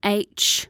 Phonics H H